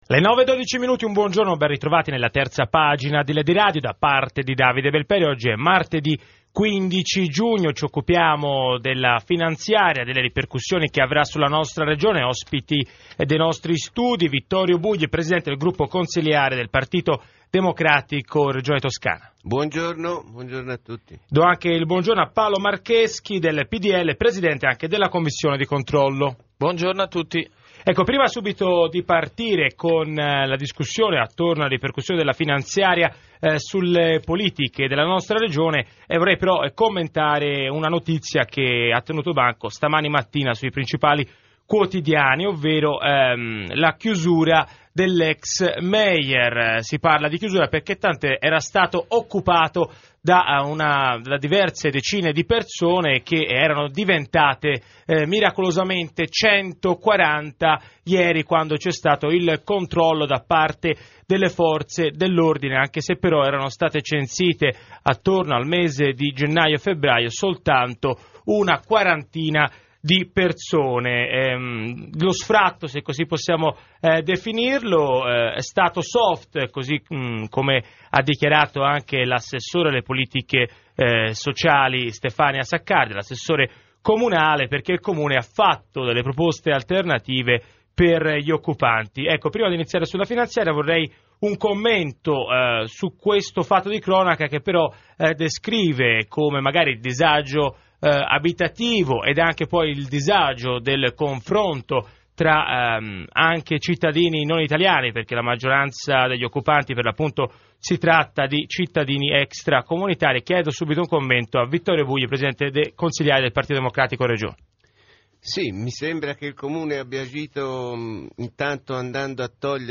Martedì 15 giugno sono stato ospite della trasmissione radiofonica "Terza pagina" di Lady Radio. Argomento: la manovra finanziaria del governo Berlusconi. E' stato interessante sentire cosa ne pensa la gente che interagiva in trasmissione con sms e telefonate in diretta.